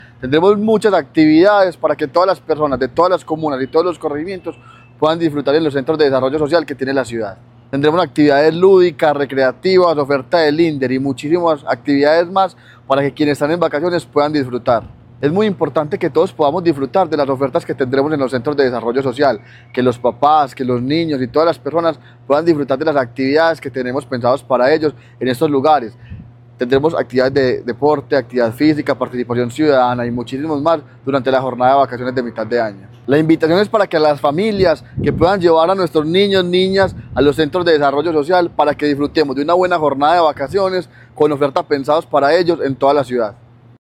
Declaraciones secretario de Participación Ciudadana, Camilo Cano Montoya
Declaraciones-secretario-de-Participacion-Ciudadana-Camilo-Cano-Montoya-1.mp3